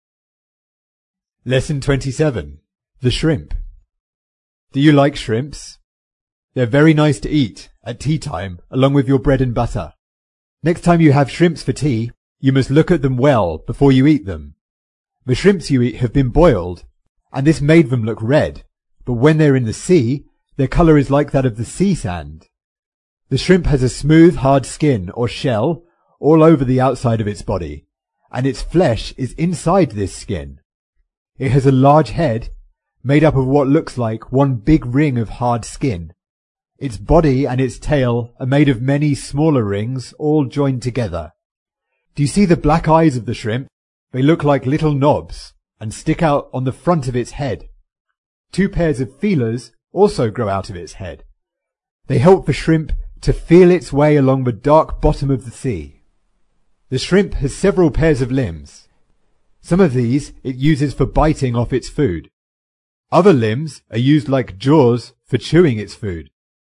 在线英语听力室提供配套英文朗读与双语字幕，帮助读者全面提升英语阅读水平。